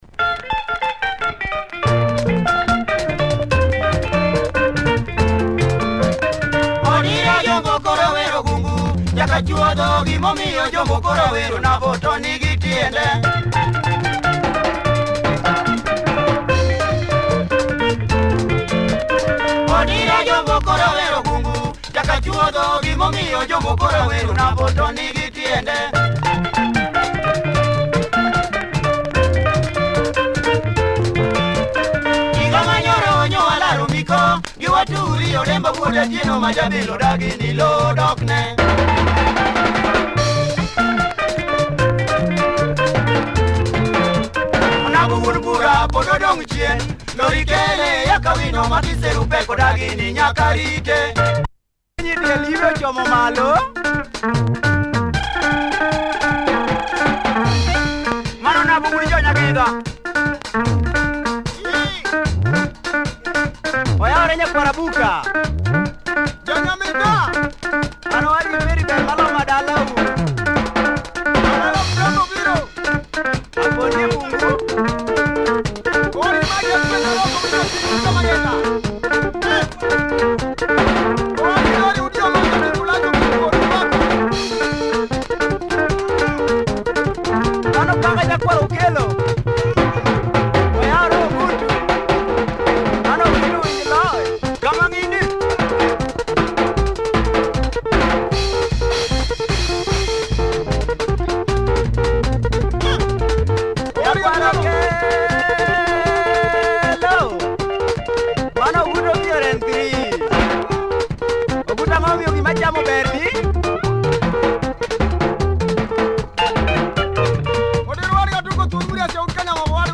Heavy LUO benga here, uptempo mode!